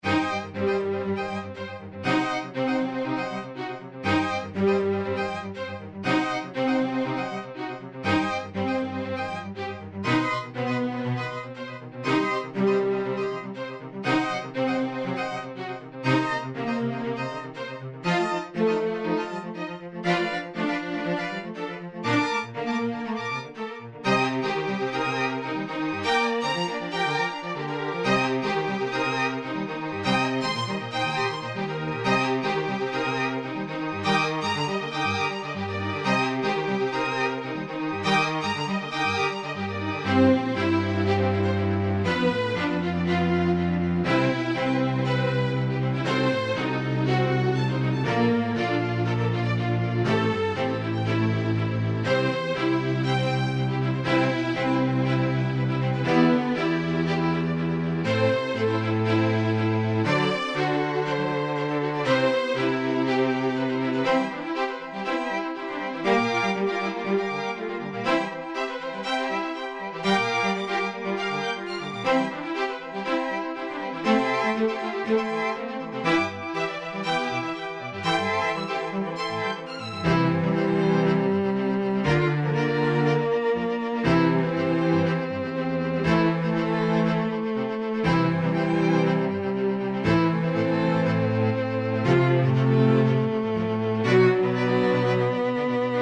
ストリング アンサンブル1、バイオリン、ビオラ、チェロ